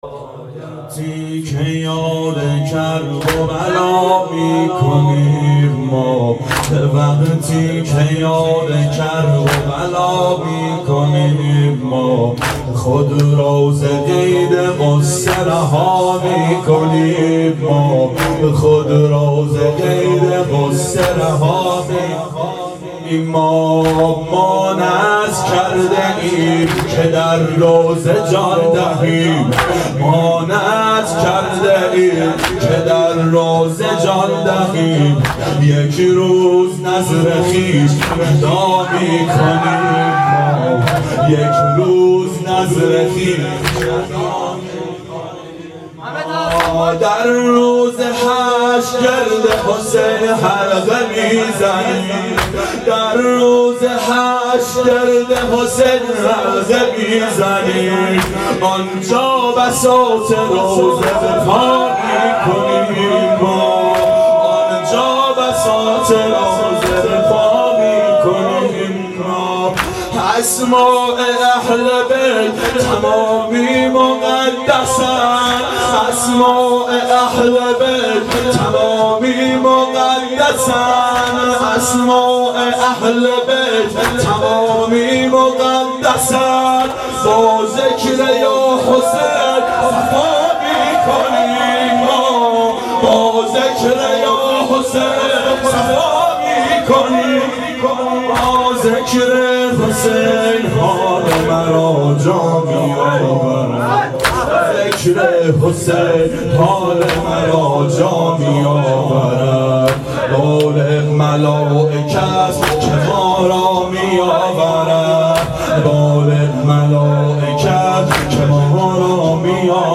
• شب اربعین 92 هیأت عاشقان اباالفضل علیه السلام منارجنبان